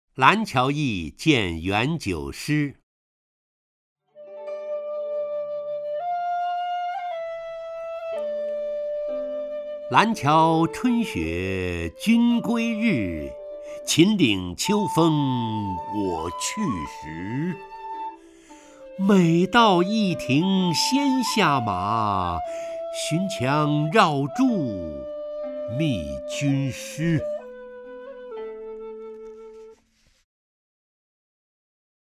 陈醇朗诵：《蓝桥驿见元九诗》(（唐）白居易) （唐）白居易 名家朗诵欣赏陈醇 语文PLUS